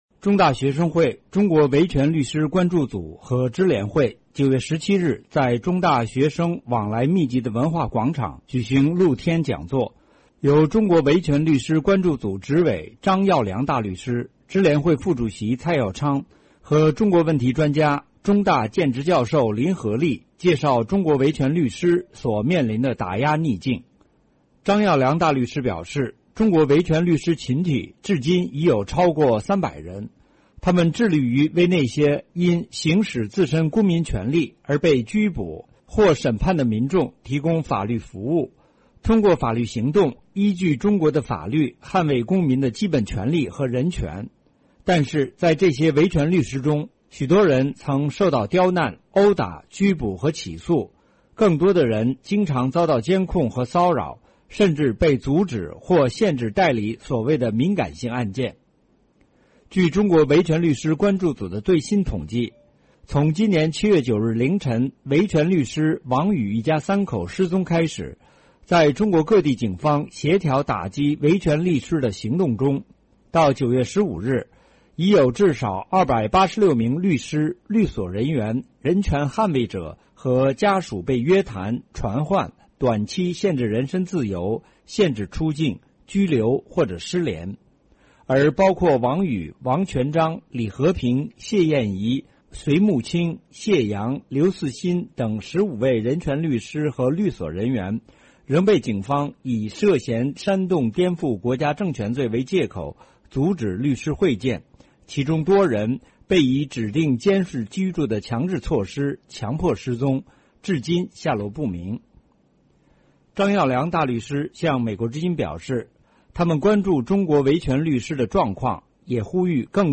香港几个团体星期四下午在中文大学举行声援中国维权律师的讲座，向学生介绍维权律师群体以及他们目前所遭受的打压状况，呼吁港人关注中国的维权律师和公民社会，阻止内地的专制文化蔓延到香港。